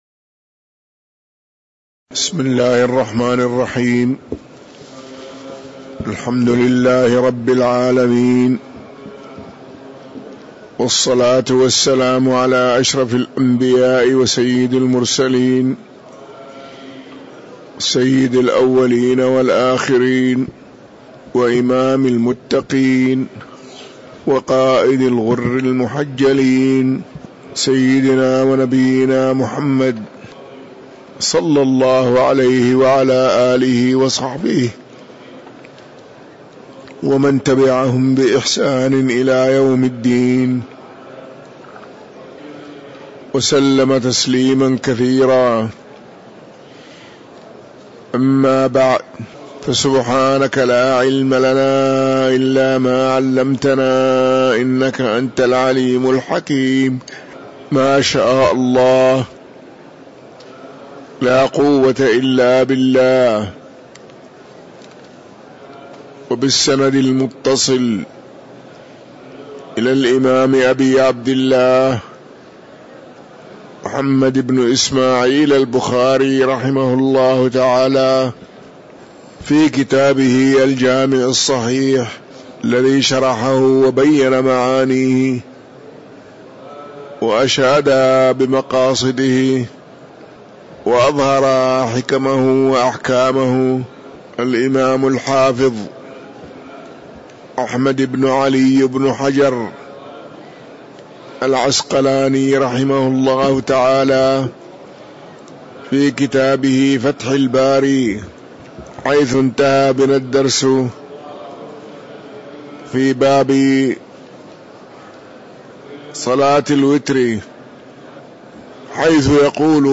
تاريخ النشر ٢٩ رجب ١٤٤٣ هـ المكان: المسجد النبوي الشيخ